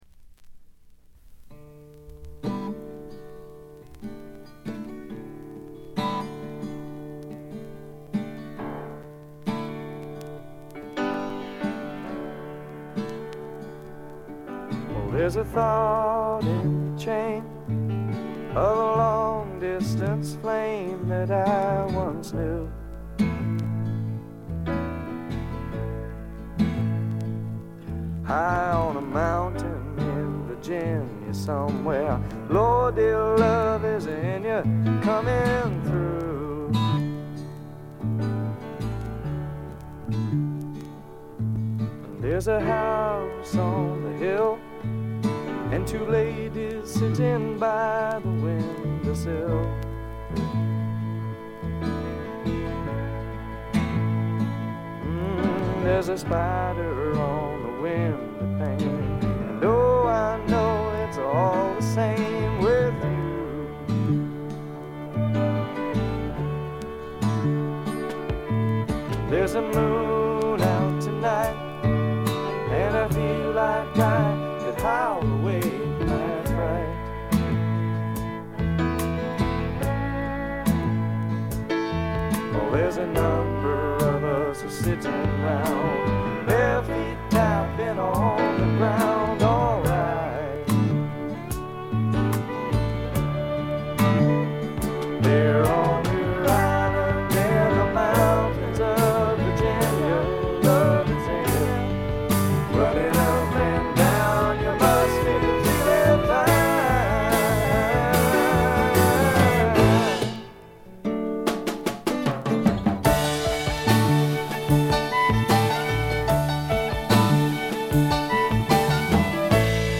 静音部での軽微なバックグラウンドノイズ、ところどころで軽微なチリプチ程度。
弾き語りに近いものとバンドサウンドとの配合の妙も素晴らしく、だれることなく最後まで完結しています。
とても完成度が高い素晴らしいシンガーソングライター作品。
ちょっとドリーミーな空気が漂うあたりが人気の秘密でしょうか。
試聴曲は現品からの取り込み音源です。